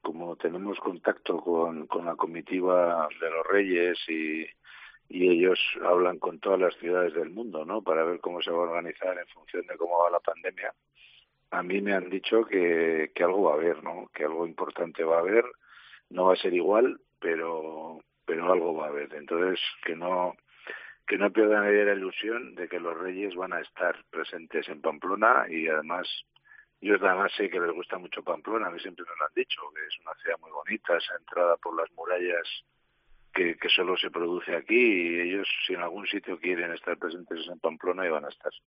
Enrique Maya, alcalde de Pamplona, confirma que "algo va a haber" el día de la Cabalta de los Reyes Magos